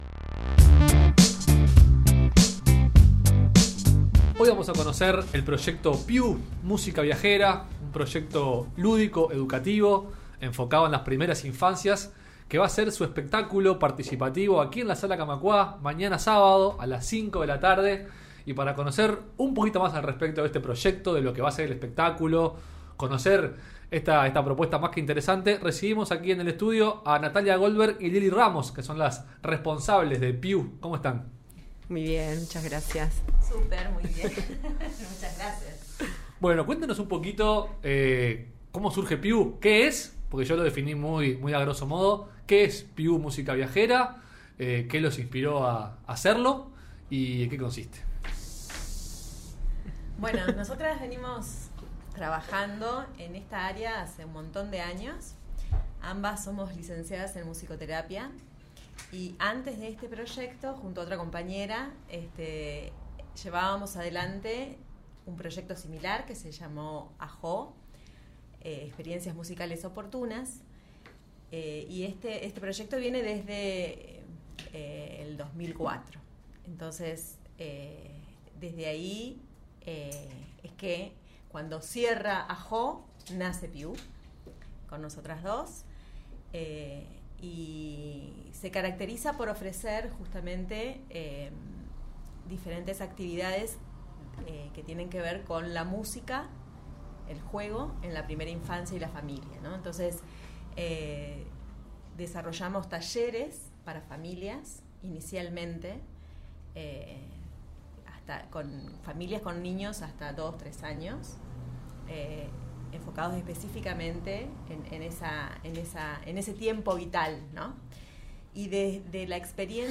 En entrevista con Radio Fénix